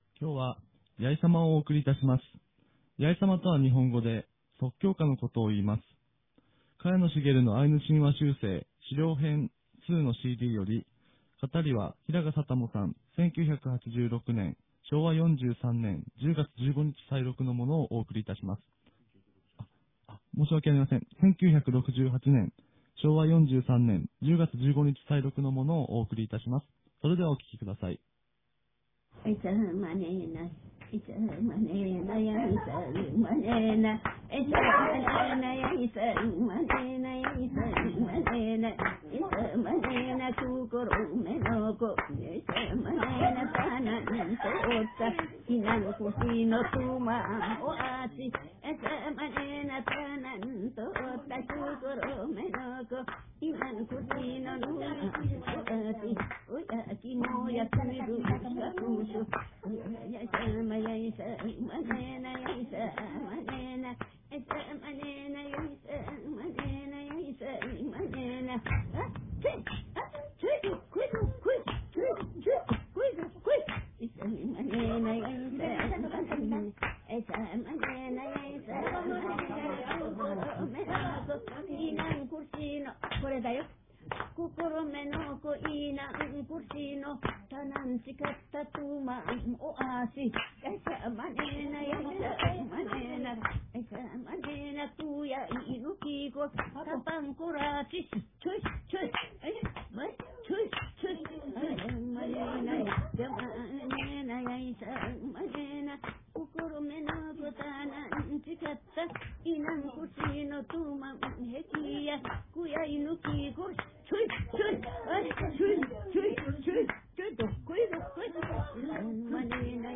■ヤイサマ（即興歌）